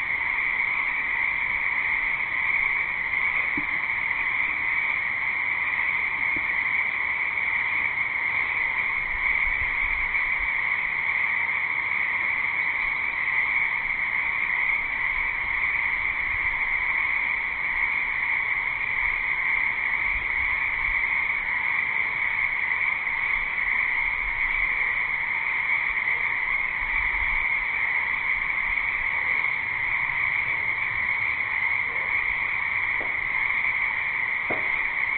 标签： 音景 氛围 环境 青蛙 氛围 性质 现场记录
声道立体声